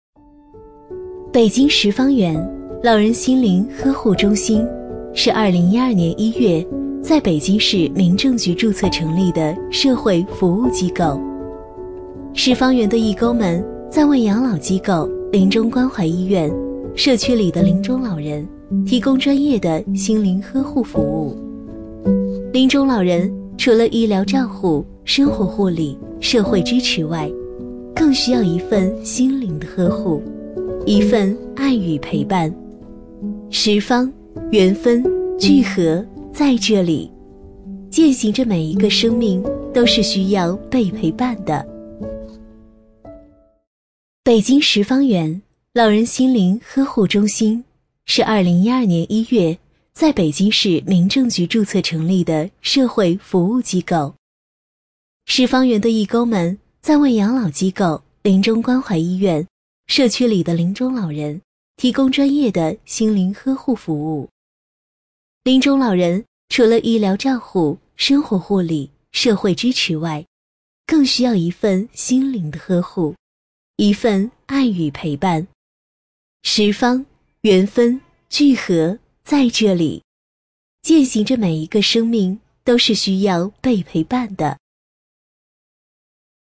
【女50号专题】温馨讲述-十方缘养老院
【女50号专题】温馨讲述-十方缘养老院.mp3